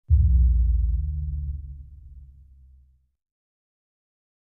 SubImpactSweetener FS049101
Sub Impact Sweetener; Hollow And Very Low Soft Thud With Tone And Pitch Drop. - Fight Sweetener